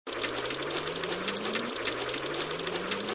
roulette_wheelspin.mp3